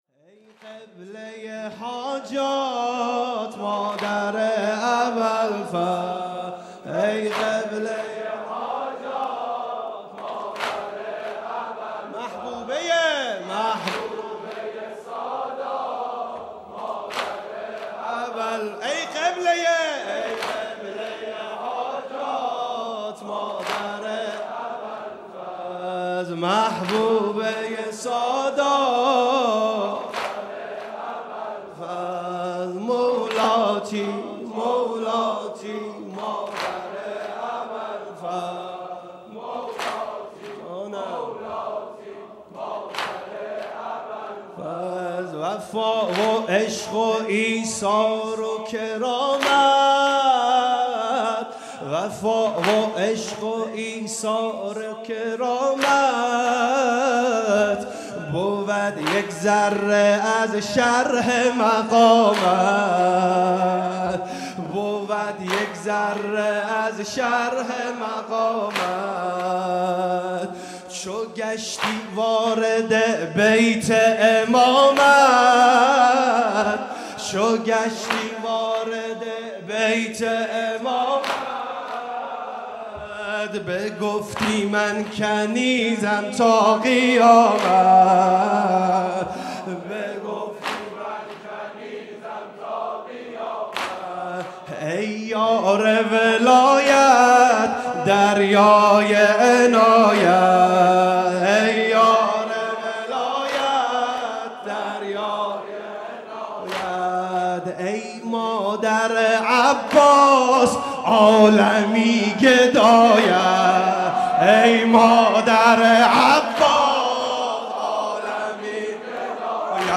گلچين محرم 95 - واحد - مادر ابالفضل